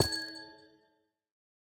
Minecraft Version Minecraft Version latest Latest Release | Latest Snapshot latest / assets / minecraft / sounds / block / amethyst_cluster / place1.ogg Compare With Compare With Latest Release | Latest Snapshot